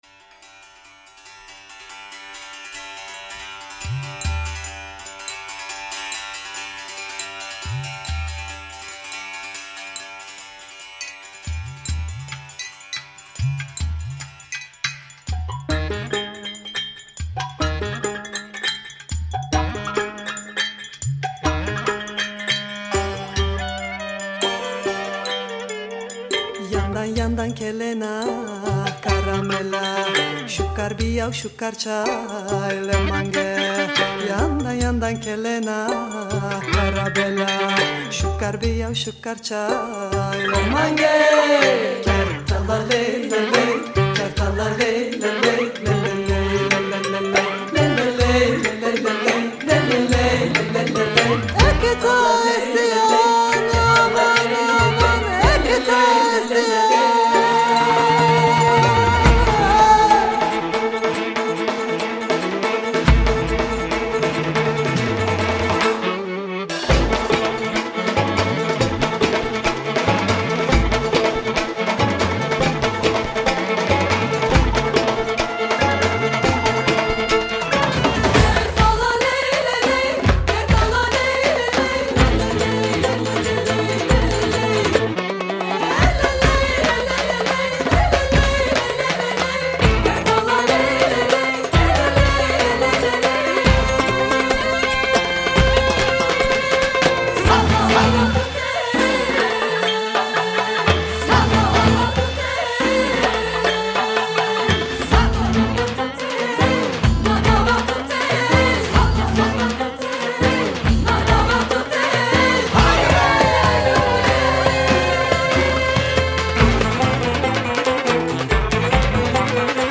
Harika bir roman havas�..